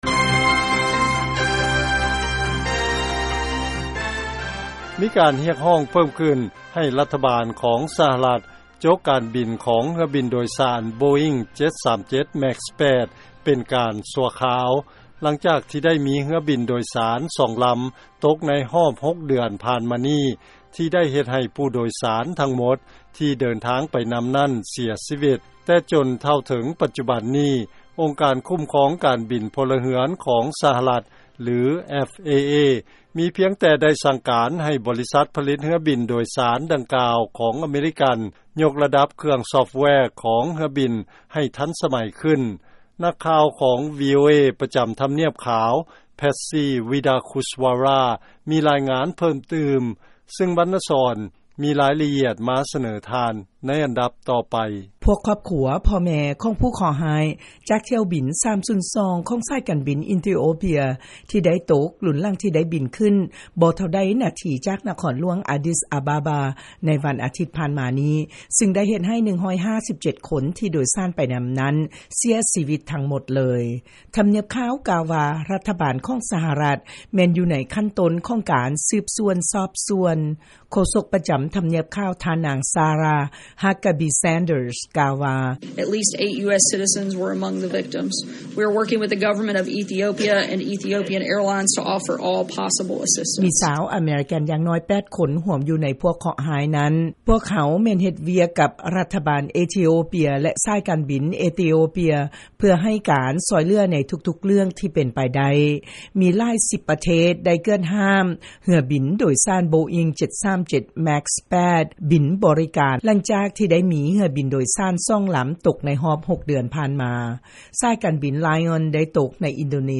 ລາຍງານກ່ຽວກັບການຮຽກຮ້ອງບໍ່ໃຫ້ເຮືອບິນໂບອິ້ງ 737 ແມັກສ໌ 8 ບິນ